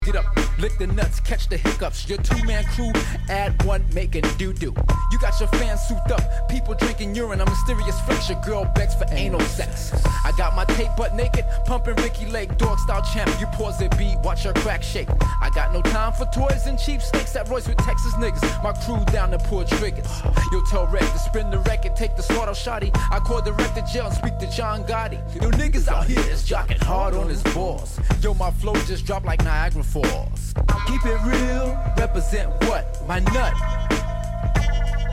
Tag       HIP HOP UNDERGROUND